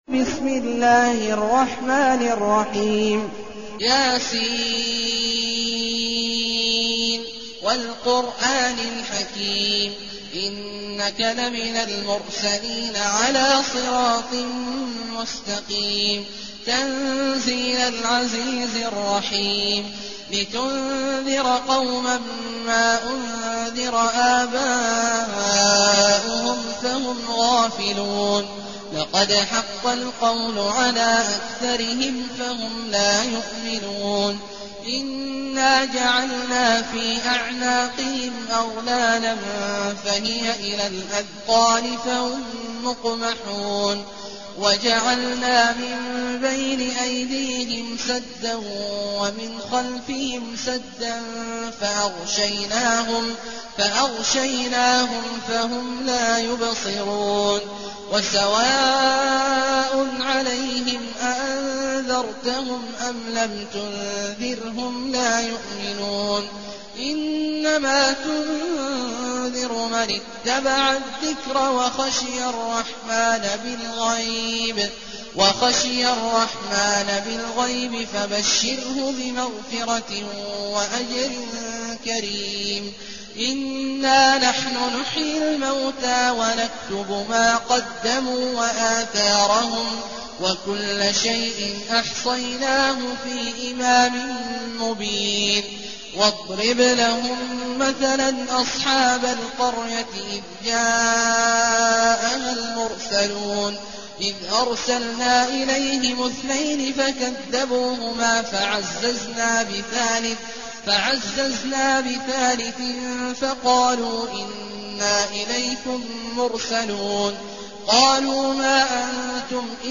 المكان: المسجد النبوي الشيخ: فضيلة الشيخ عبدالله الجهني فضيلة الشيخ عبدالله الجهني يس